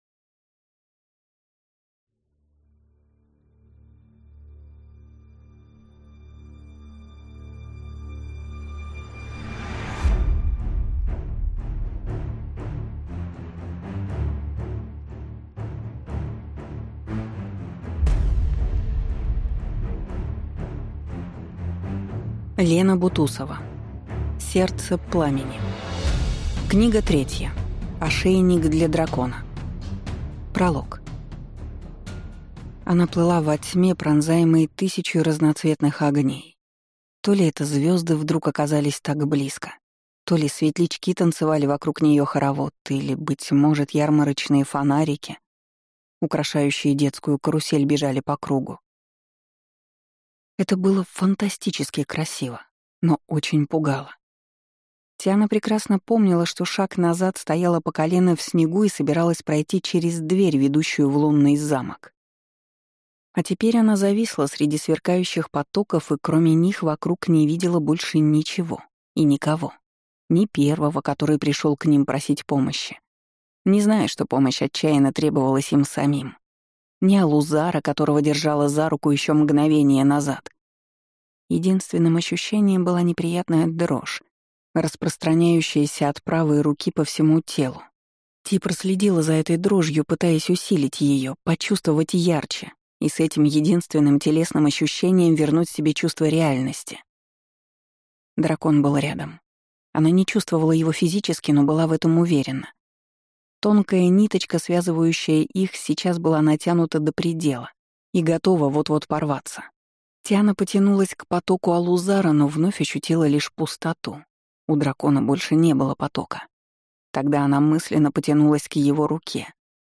Аудиокнига Сердце Пламени. Книга 3. Ошейник для дракона | Библиотека аудиокниг